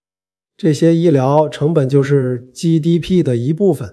f5tts - F5-TTS wrap module